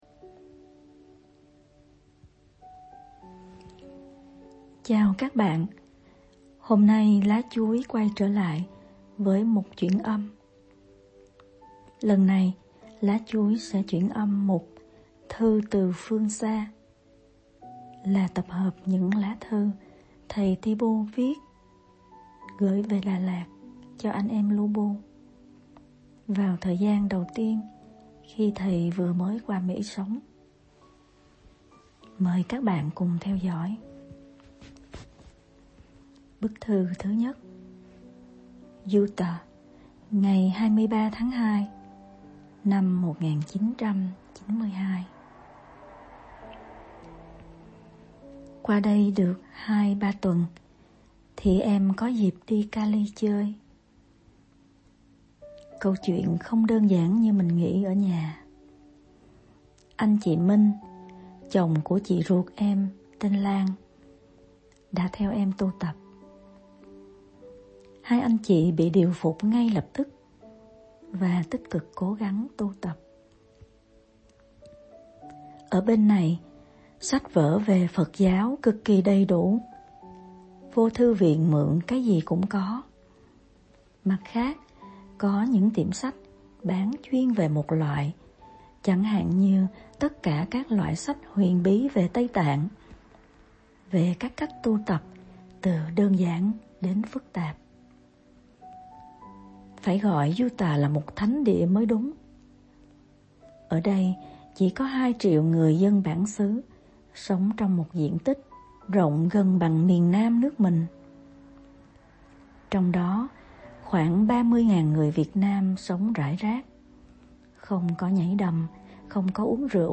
Mùa đông lạnh giá, con đợi đêm khuya vắng cho bớt xe cộ chạy ngang nhà, rồi con trùm mền đọc và thâu âm bằng điện thoại nên nhiều chỗ cũng khó lọc được tạp âm khi xe chạy ngang qua, mọi người nghe thấy có ồn thì thông cảm cho con hỉ !